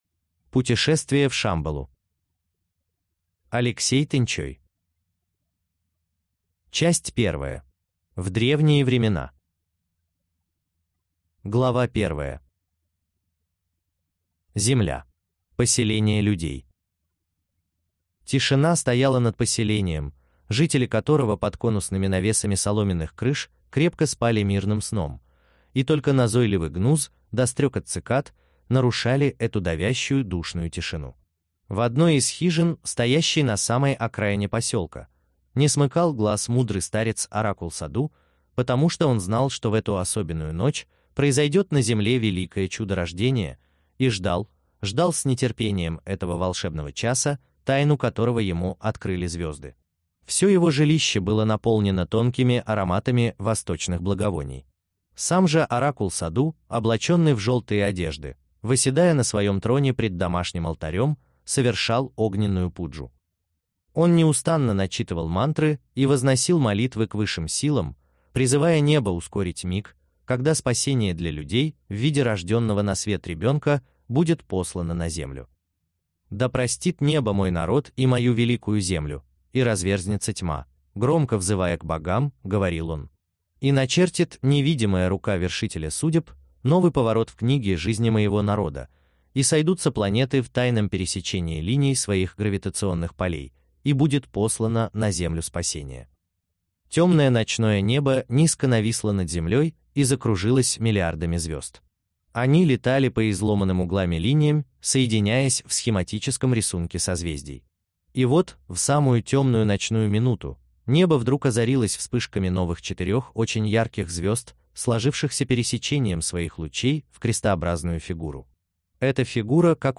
Аудиокнига Путешествие в Шамбалу | Библиотека аудиокниг